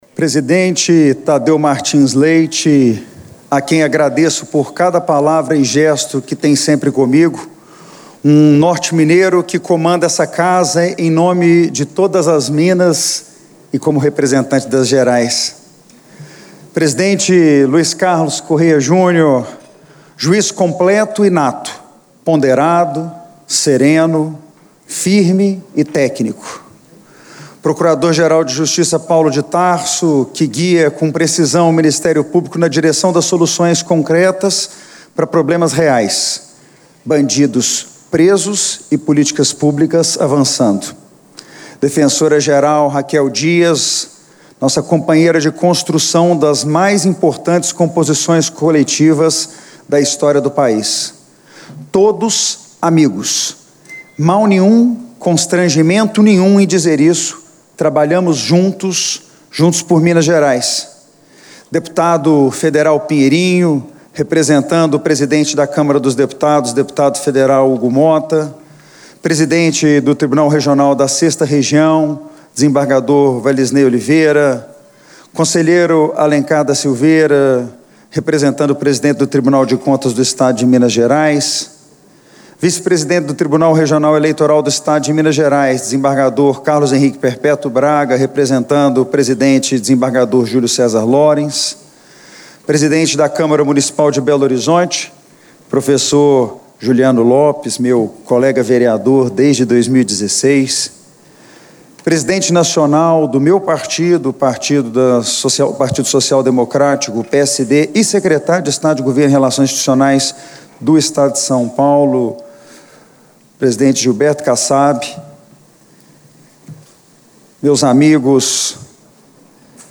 Durante reunião solene de posse, no Plenário, o novo governador mineiro destacou em seu pronunciamento as demandas coletivas criadas pelo tamanho do estado como desafio do mandato que vai até janeiro de 2027, mas apontou a necessidade de uma gestão que resolva problemas do cidadão comum, criados muitas vezes pela burocracia.
Íntegra: Mateus Simões faz primeiro pronunciamento como governador de Minas